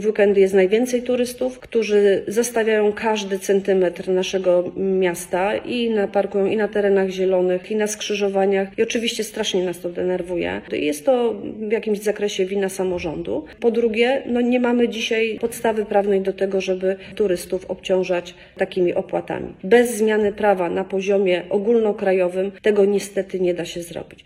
To, jak komentuje prezydent Joanna Agatowska, szczególnie w uzdrowiskowej dzielnicy Świnoujścia powoduje duże niedogodności dla mieszkańców.